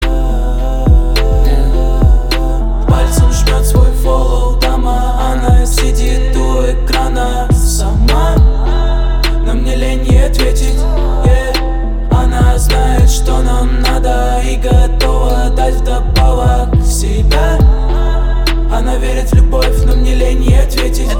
• Качество: 320, Stereo
красивые
Хип-хоп
спокойные